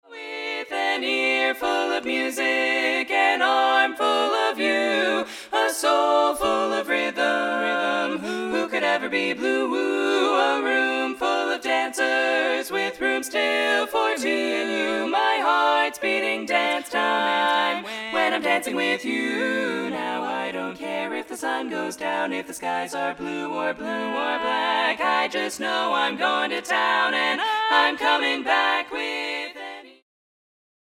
barbershop swing song